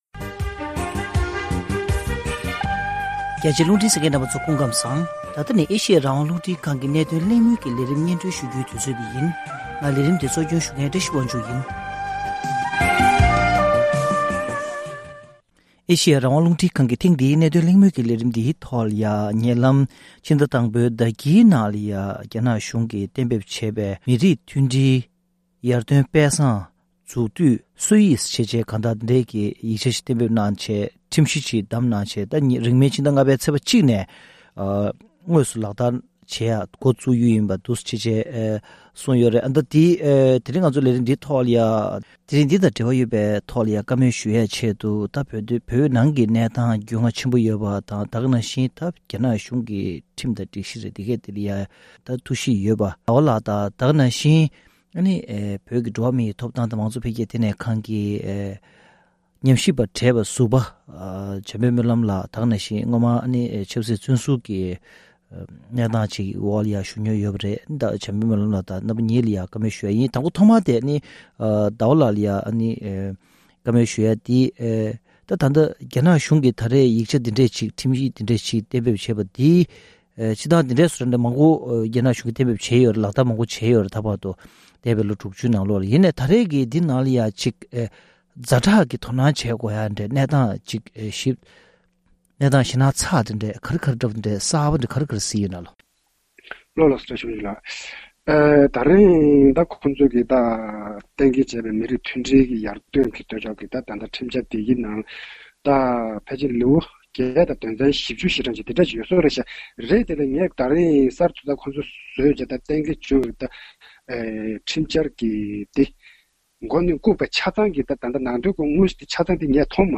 ༄༅༎ཐེངས་འདིའི་གནད་དོན་གླེང་མོལ་གྱི་ལས་རིམ་ནང་།